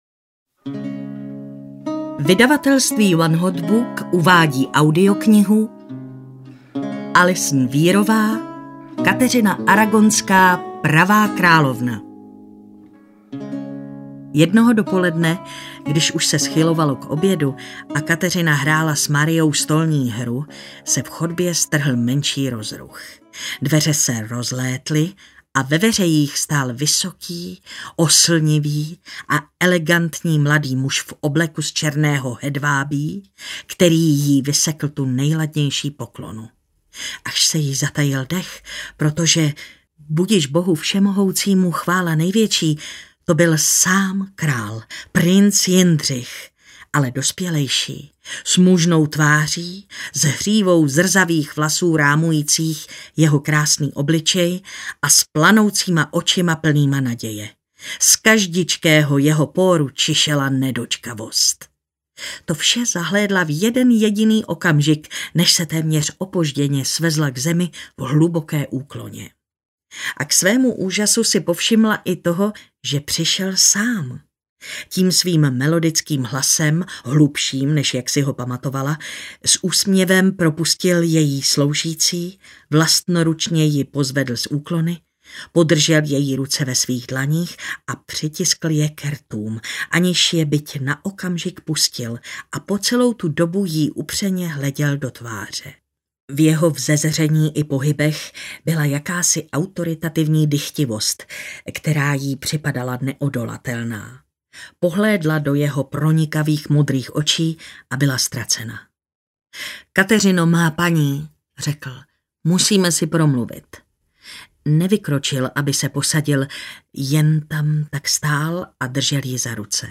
Audio knihaKateřina Aragonská: Pravá královna
Ukázka z knihy
• InterpretMartina Hudečková